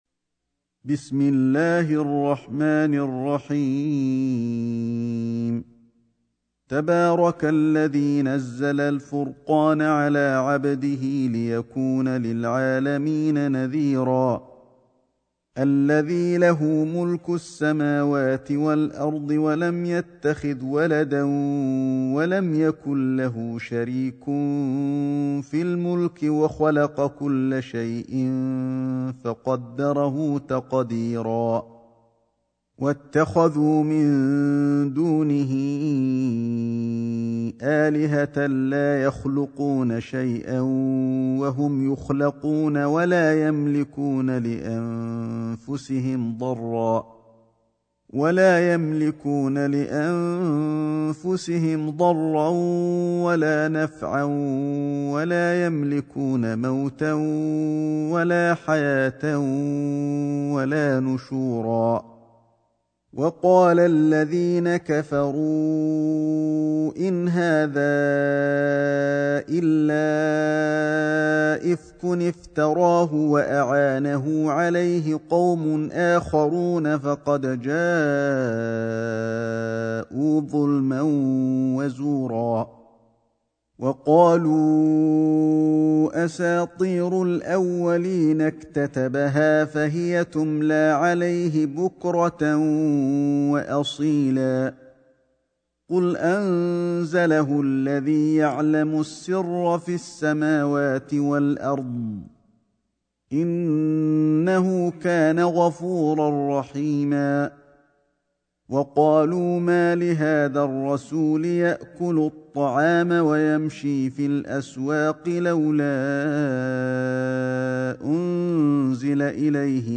سورة الفرقان > مصحف الشيخ علي الحذيفي ( رواية شعبة عن عاصم ) > المصحف - تلاوات الحرمين